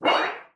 /files/sounds/material/metal/sheet/sheet01.mp3